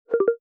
Teams 召唤.mp3